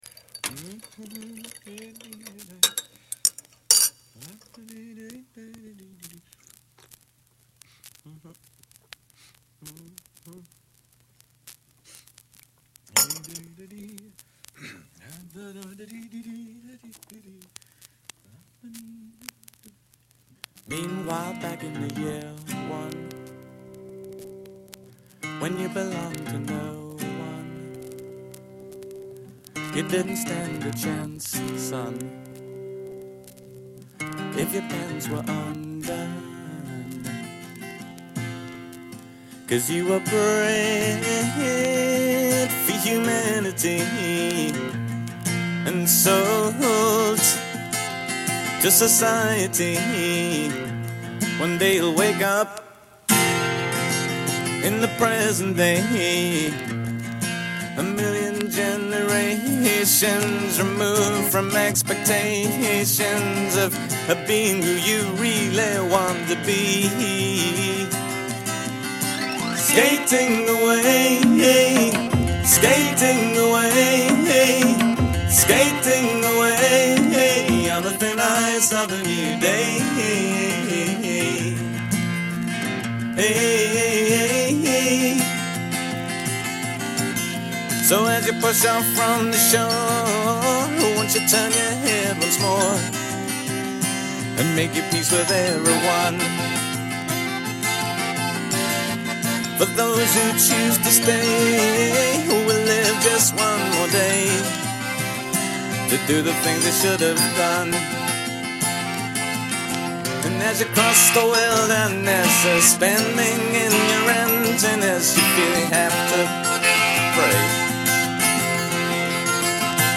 on vinyl